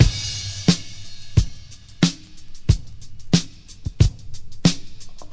• 90 Bpm Breakbeat Sample F Key.wav
Free drum groove - kick tuned to the F note.
90-bpm-breakbeat-sample-f-key-Wne.wav